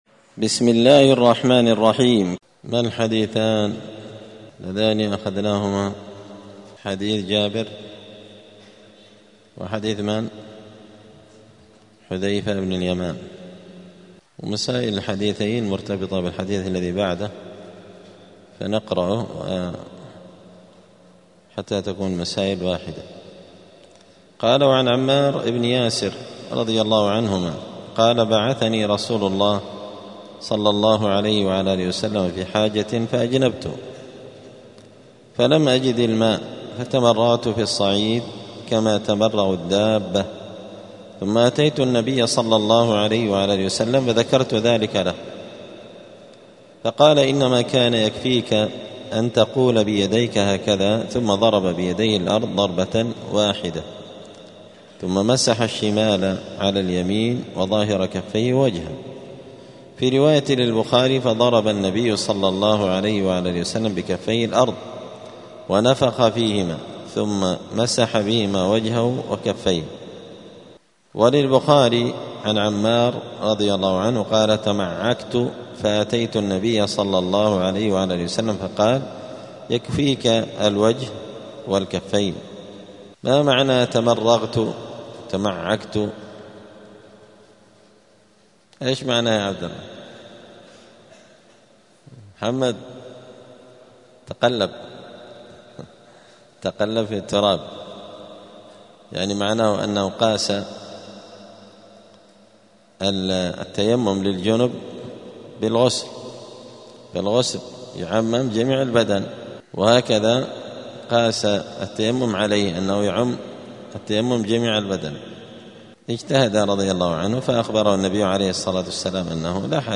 دار الحديث السلفية بمسجد الفرقان قشن المهرة اليمن
*الدرس الواحد والتسعون [91] {باب صفة التيمم حكم الترتيب بين الوجه واليدين في التيمم}*